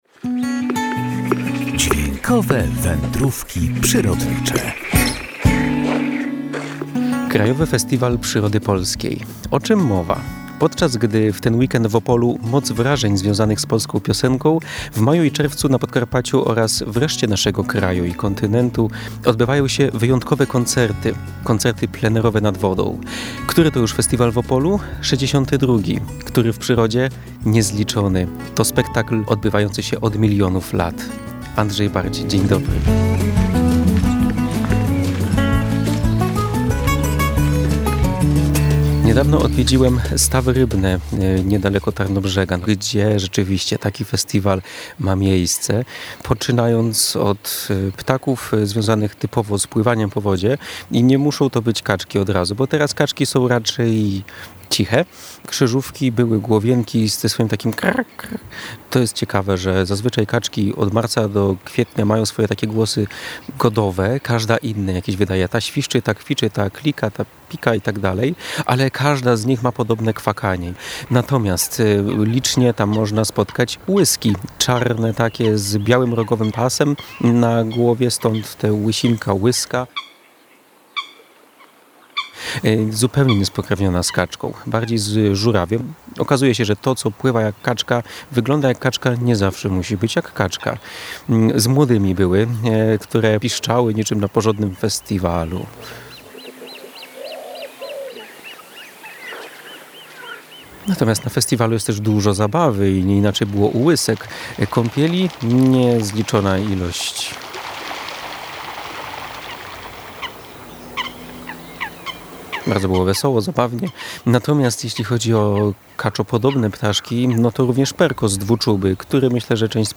Podczas, gdy w ten weekend w Opolu będzie moc wrażeń związanych z polską piosenką, w maju i czerwcu na Podkarpaciu oraz w reszcie naszego kraju i kontynentu odbywają się wyjątkowe koncerty – koncerty plenerowe nad wodą.